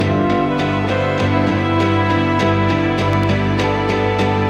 суть проблемы: ровняю запись гитары стрейч маркерами, гитара играет прямой ритм, ударов много, гитарист кривой (я), следовательно, маркер приходится добавлять почти на каждый удар. после рендера вылазит куча артефактов, похожих на потрескивание или звук задержки, когда выставил слишком маленький...